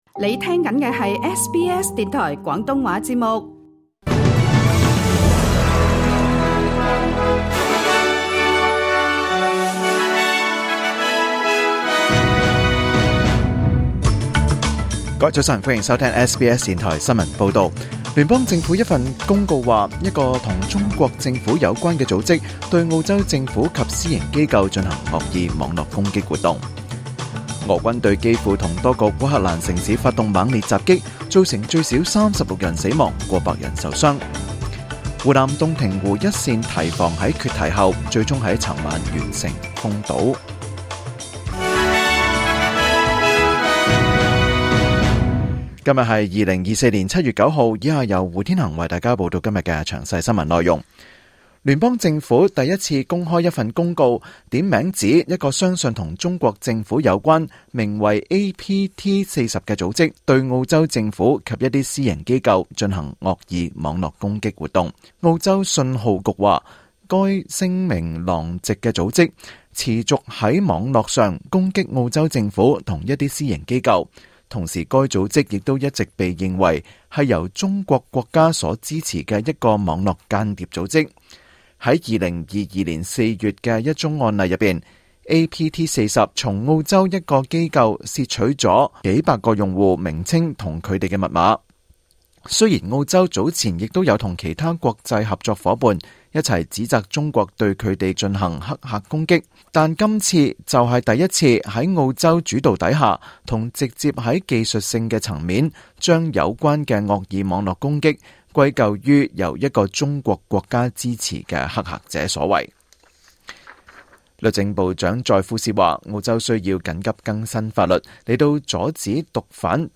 2024年7月9日SBS廣東話節目詳盡早晨新聞報道。